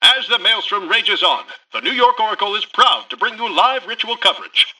[[Category:Newscaster voicelines]]
Newscaster_headline_55.mp3